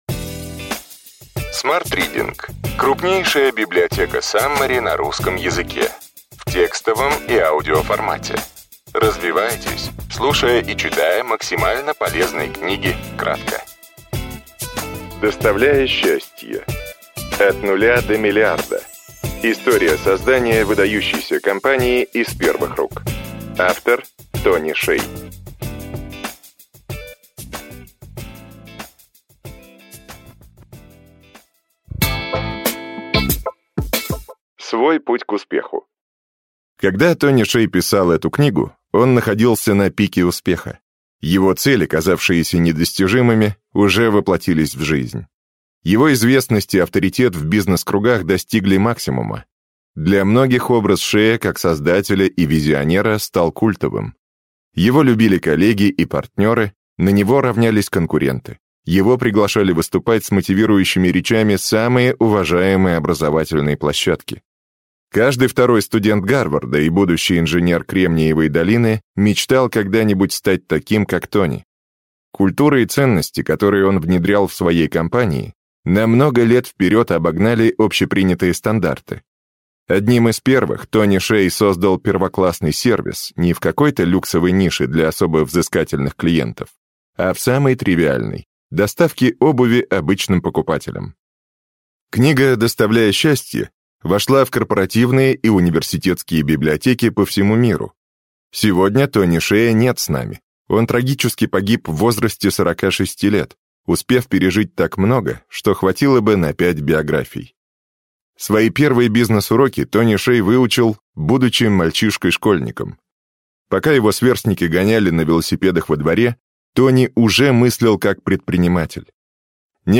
Аудиокнига Ключевые идеи книги: Доставляя счастье. От нуля до миллиарда: история создания выдающейся компании из первых рук.